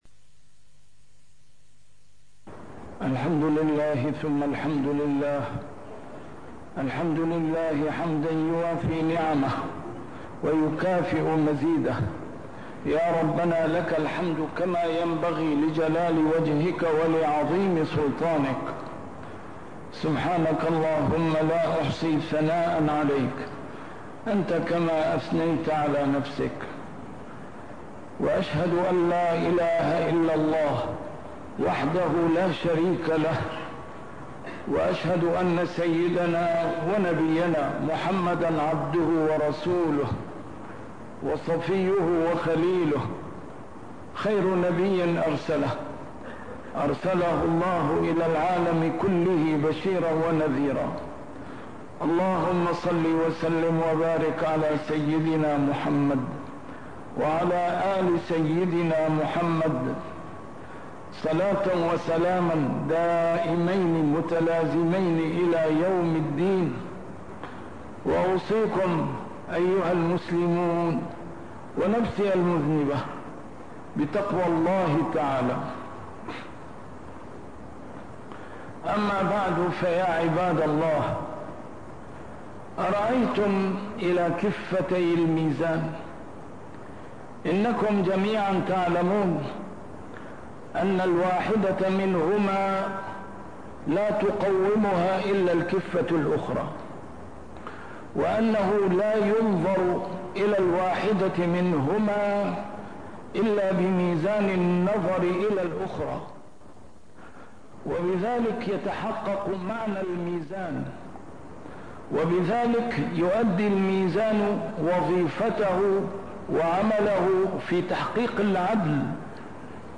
A MARTYR SCHOLAR: IMAM MUHAMMAD SAEED RAMADAN AL-BOUTI - الخطب - كِفتان .. بهما تستقيم حياة الإنسان